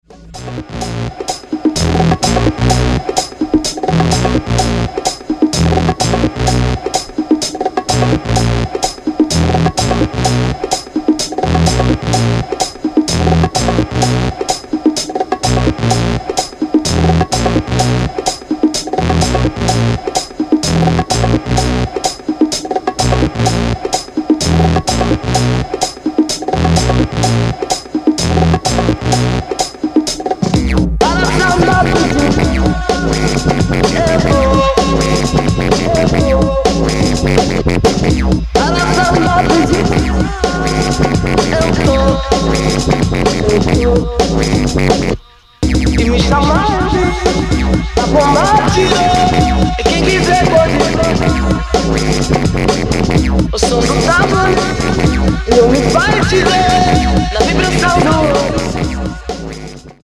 Samba flavoured house
Killer dancefloor flava.